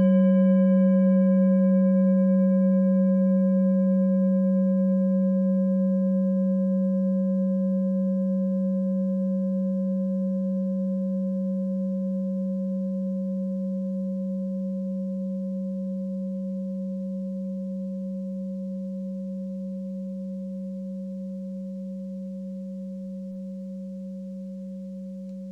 Klangschale Orissa Nr.19
(Ermittelt mit dem Filzklöppel)
In unserer Tonleiter liegt dieser Ton nahe beim "Fis".
klangschale-orissa-19.wav